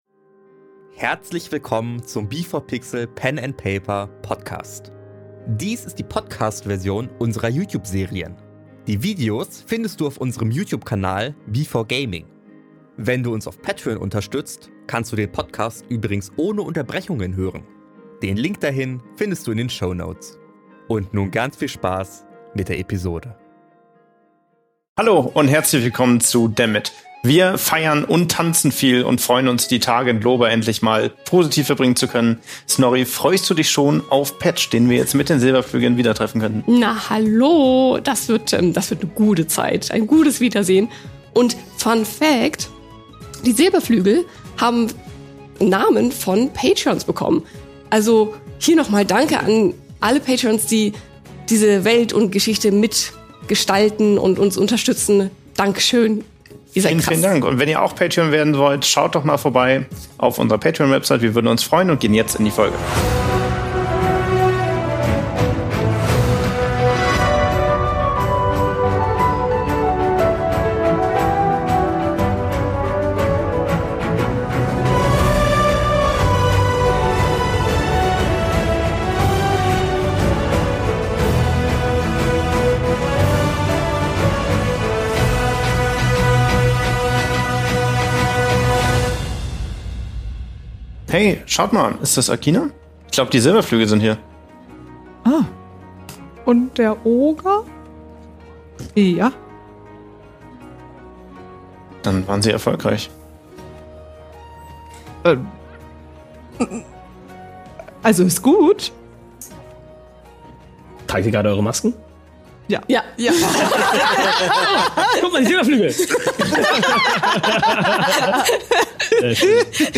Dies hier ist die Podcast-Version mit Unterbrechungen.